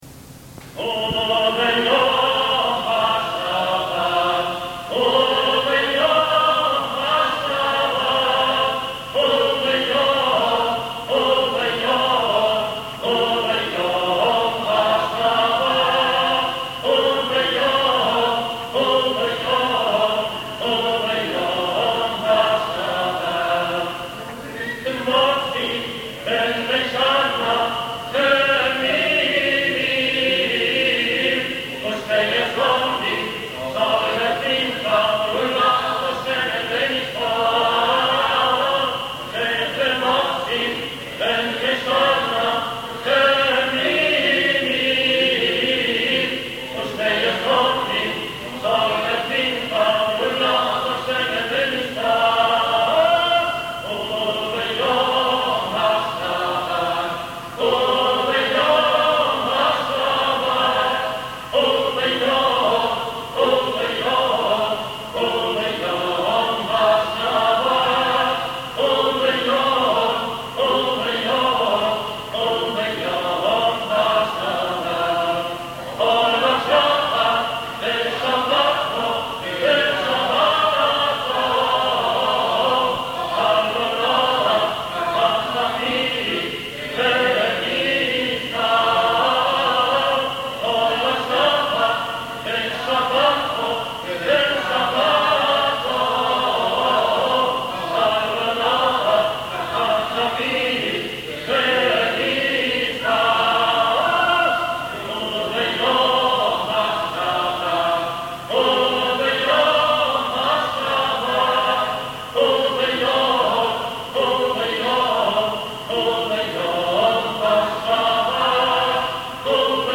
Recorded live during services.
Live with Choir